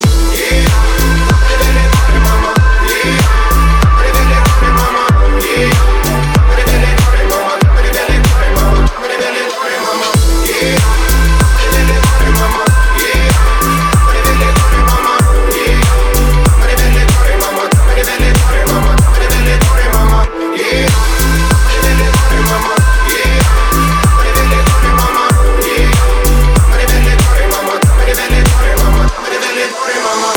мужской вокал
deep house
Club House
качающие